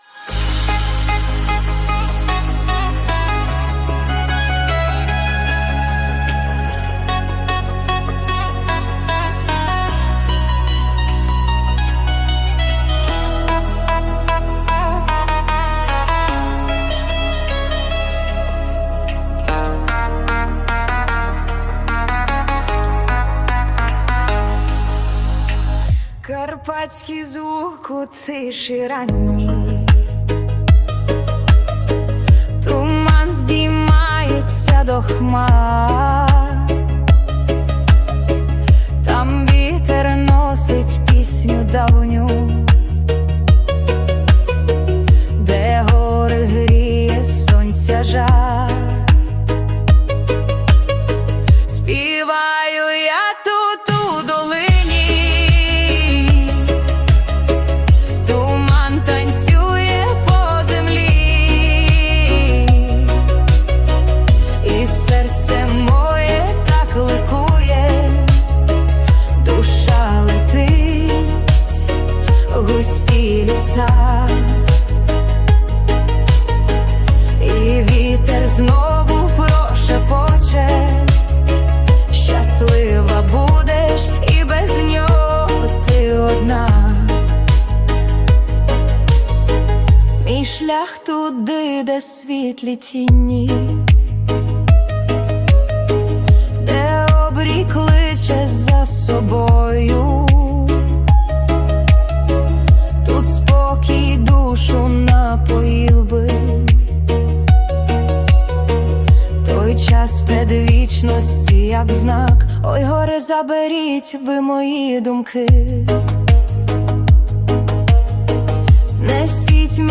Slowed Mix version
швидка версія
на скрипці і сопілці
на трубі